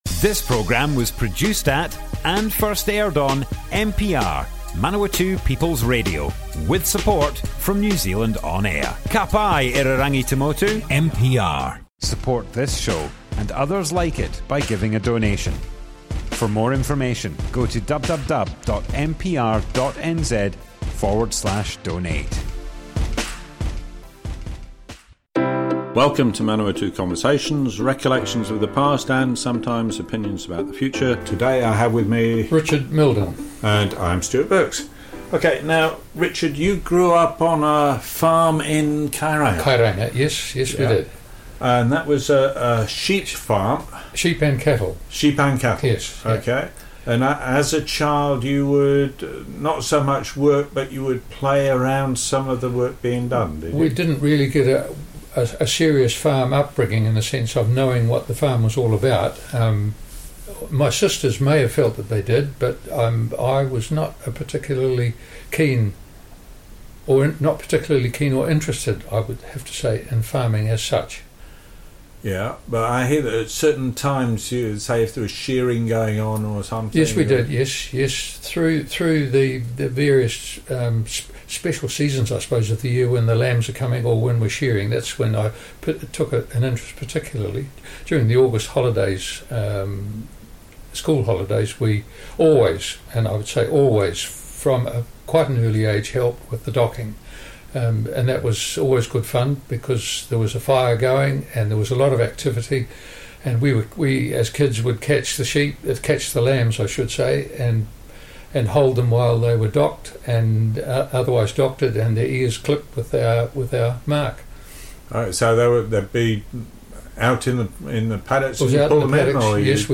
Manawatu Conversations More Info → Description Broadcast on Manawatu People's Radio, 14 September 2021.
oral history